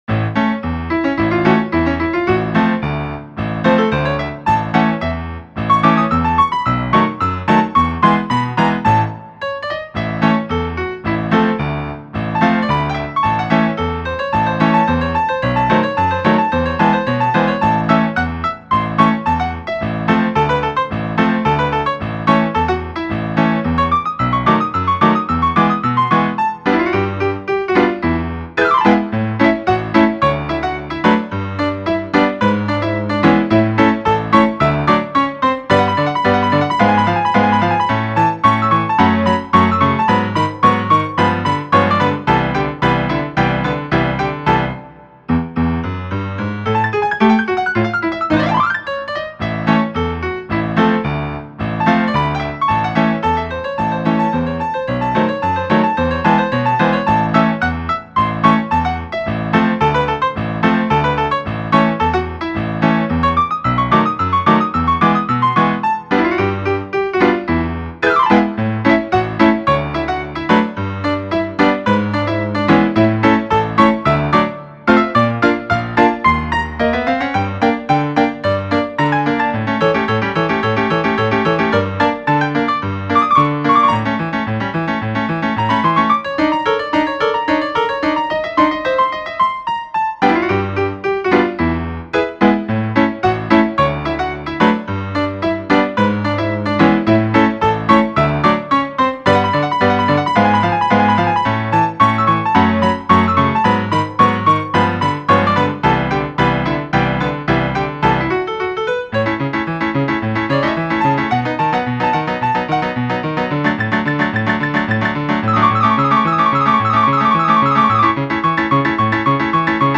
banda sonora
melodía
sintonía
Sonidos: Música